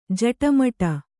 ♪ jaṭamaṭa